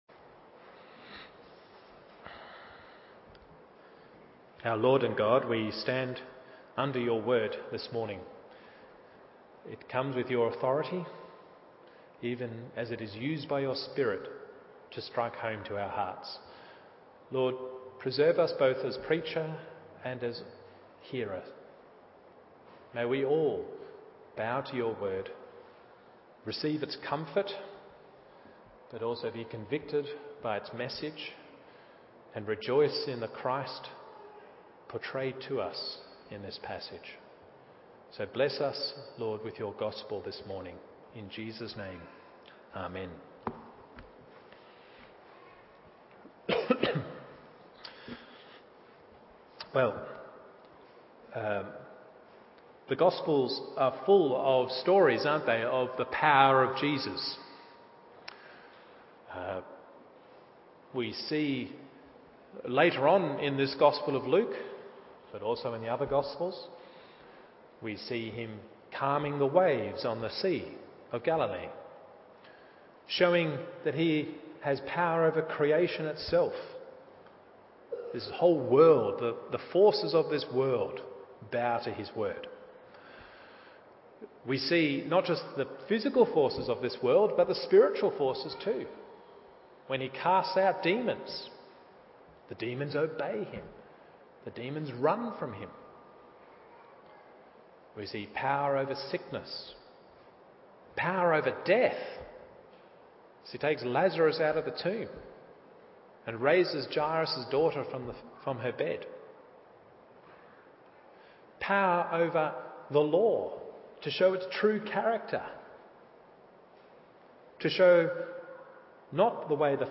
Morning Service Luke 5:17-26 1. Jesus Forgives Sins 2. Who then is He? 3. Who then are We?